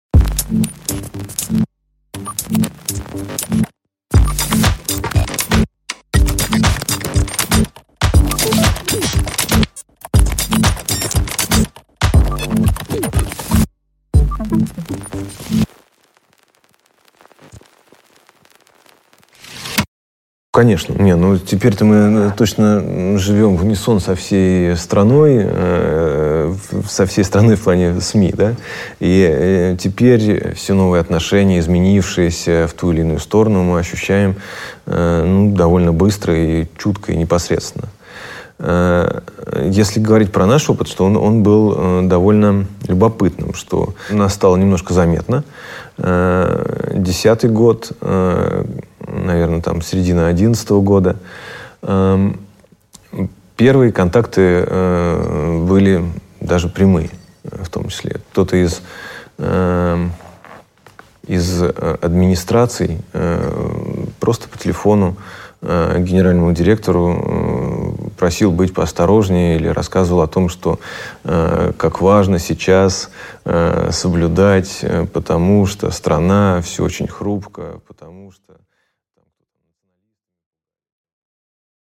Аудиокнига Новые медиа в России: история выживания | Библиотека аудиокниг